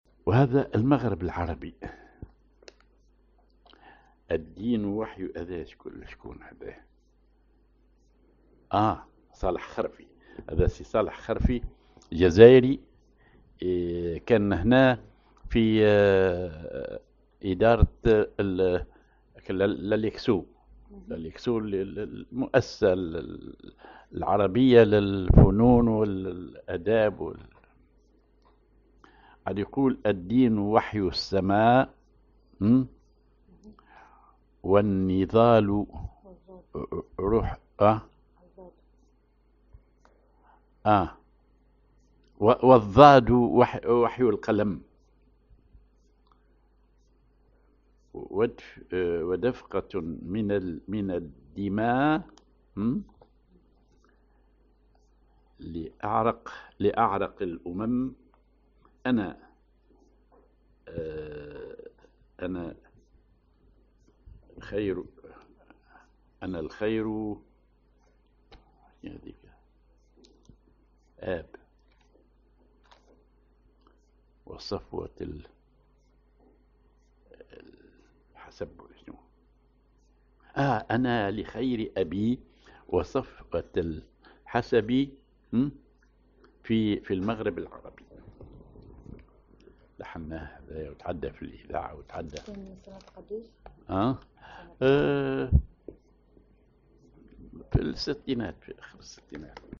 Maqam ar ماهور
genre نشيد